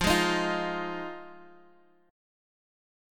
Listen to Fm7 strummed